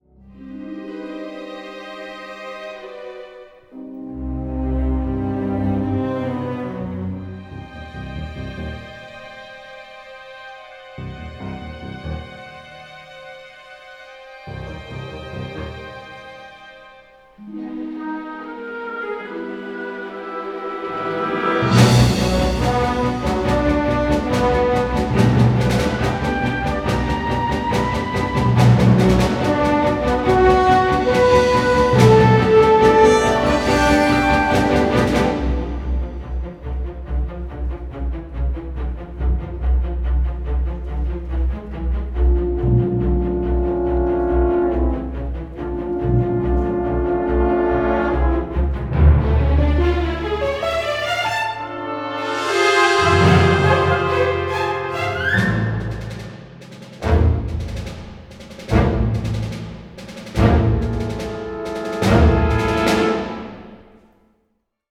delivers a poignant and deeply moving symphonic score